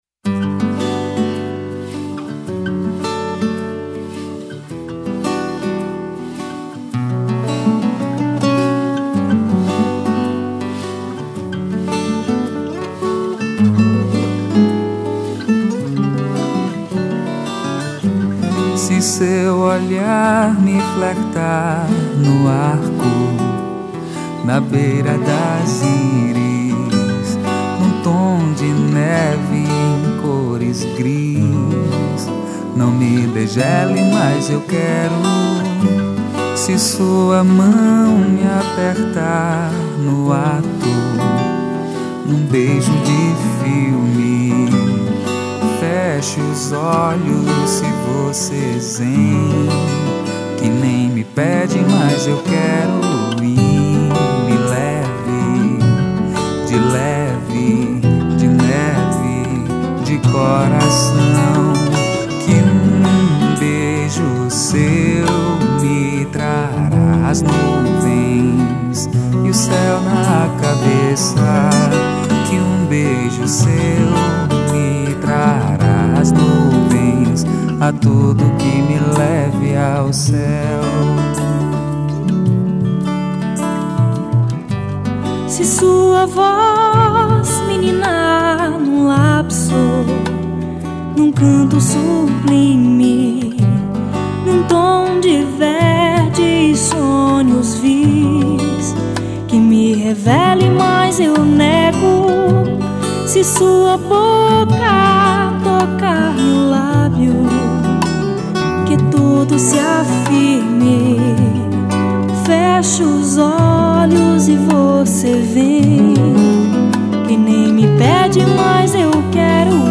829   06:10:00   Faixa:     Mpb
Clarinete, Saxofone Tenor
Voz, Violao Acústico 6